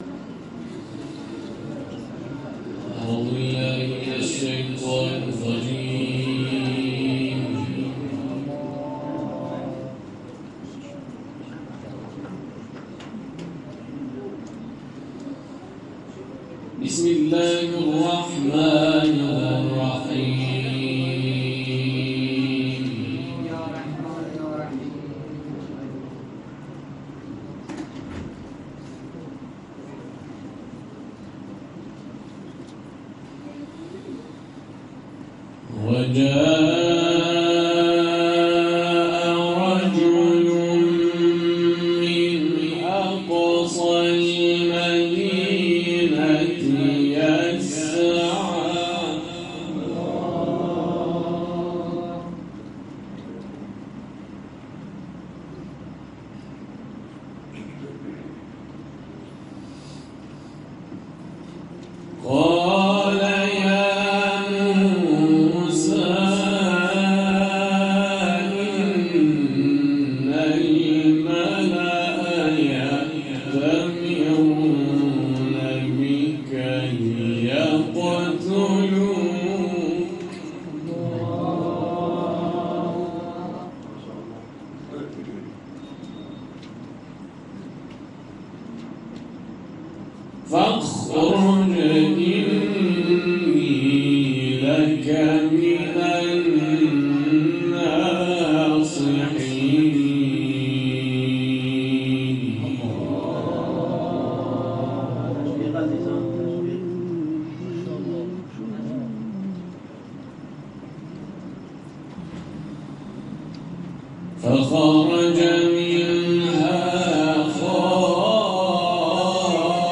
اعضای کاروان قرآنی اعزامی به مناطق مرزی سیستان و بلوچستان شب گذشته در مجمع قاریان این استان حاضر شده و به تلاوت آیاتی از کلام‌الله مجید پرداختند.
در ادامه فایل صوتی این تلاوت‌ها بارگذاری شده است.